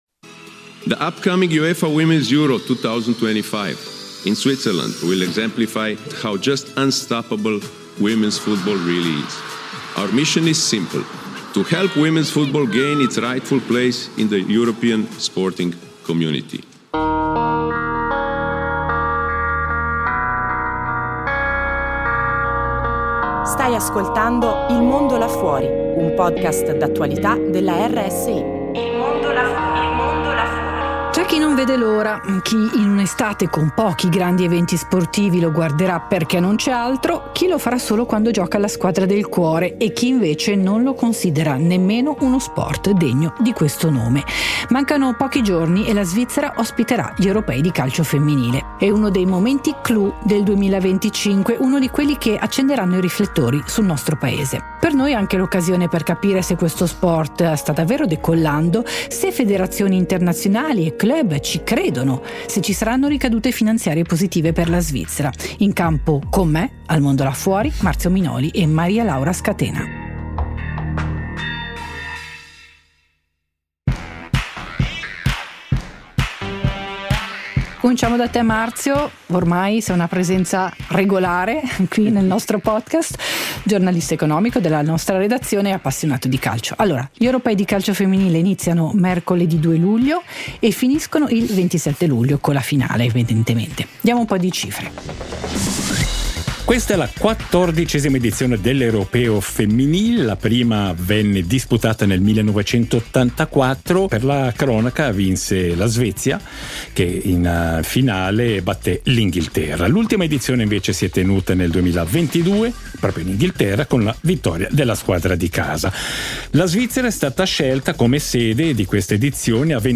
Ne parliamo con due interlocutori